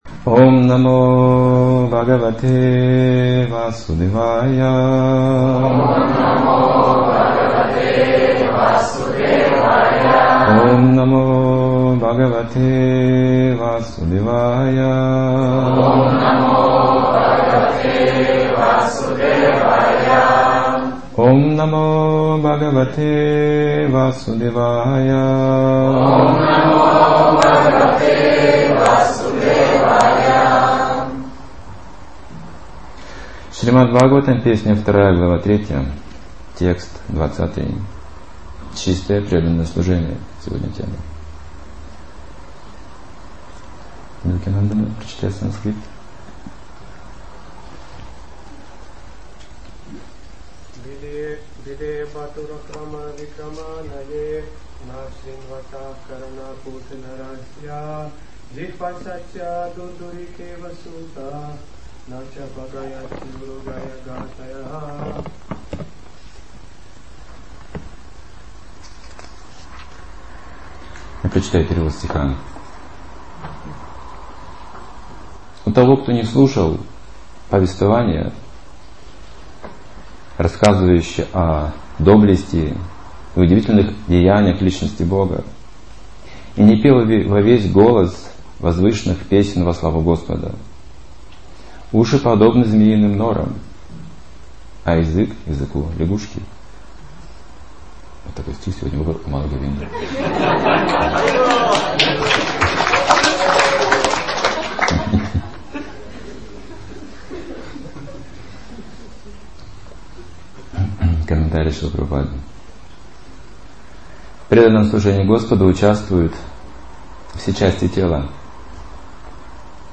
Темы, затронутые в лекции: При каких обстоятельствах раскрываются способности человека Жадный человек и бескорыстная деятельность.